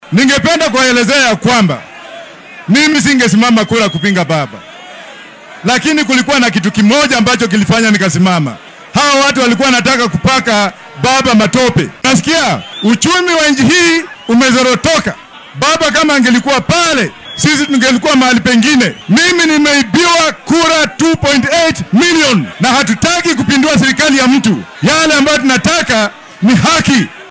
Xilli uu shalay hadal ka jeediyay fagaaraha Jacaranda ee ismaamulka Nairobi ayuu hoosta ka xarriiqay inay keliya caddaalad doonayaan.